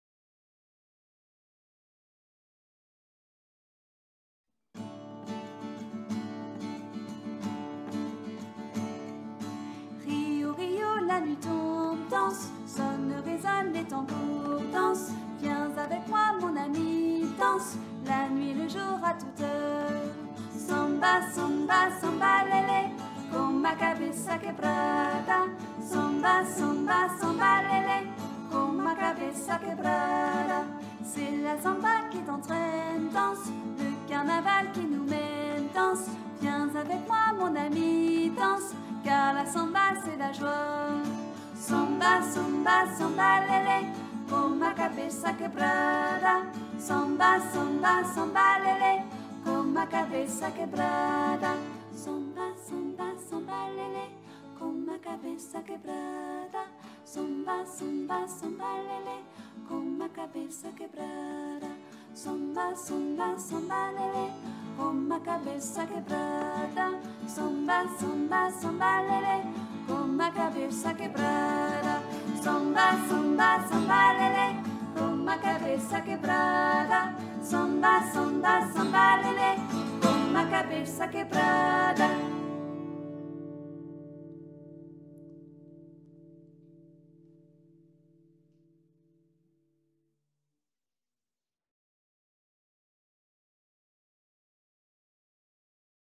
(chant pour carnaval)